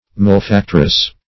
Malefactress \Mal`e*fac"tress\, n. A female malefactor.